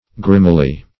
grimily - definition of grimily - synonyms, pronunciation, spelling from Free Dictionary Search Result for " grimily" : The Collaborative International Dictionary of English v.0.48: Grimily \Grim"i*ly\, adv.